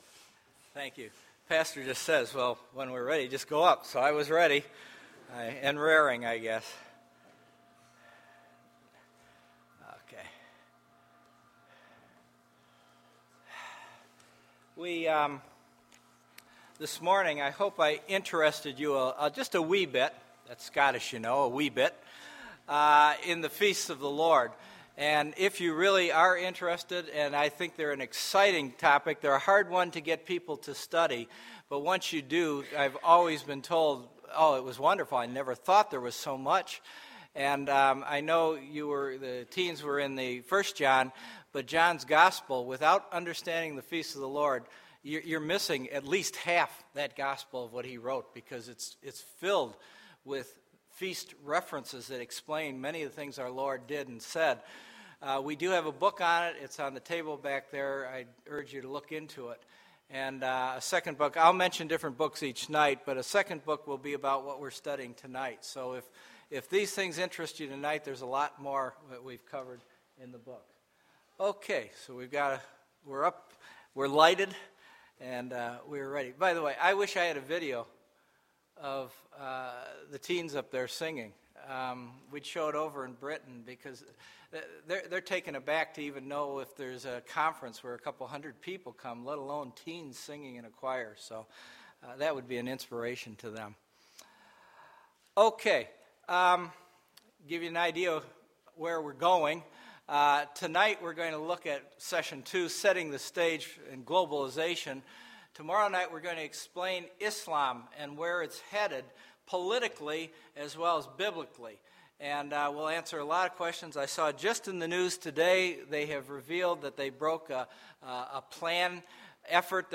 Sunday, March 25, 2012 – Spring Bible Conference – Sunday PM